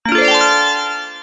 upgrade.wav